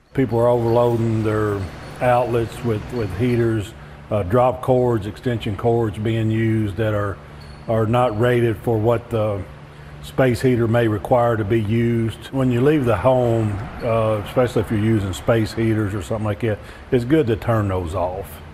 Not surprisingly, most house fires occur during cold weather.  We all agree that space heaters are nice, but fire officials say if they are not used properly, they can be dangerous.